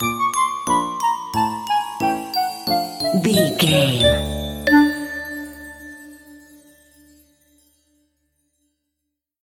Uplifting
Aeolian/Minor
D
Slow
flute
oboe
piano
percussion
silly
circus
goofy
comical
cheerful
perky
Light hearted
quirky